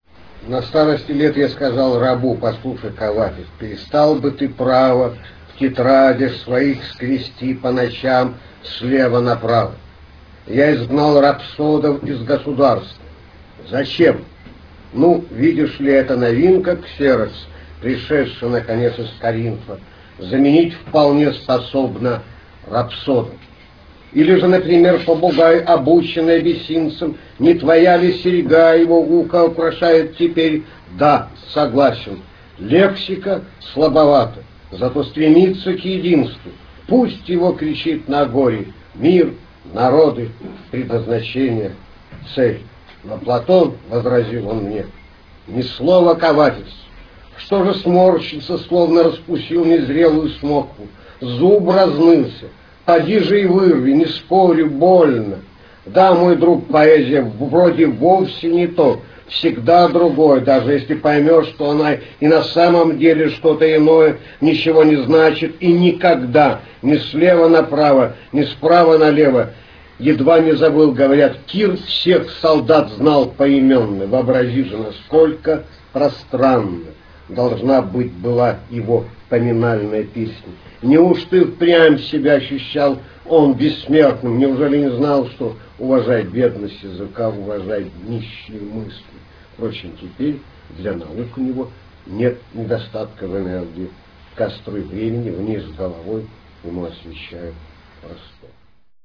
Kuuntele tekijän luenta (venäjäksi, RealAudio, 1 m 38 s)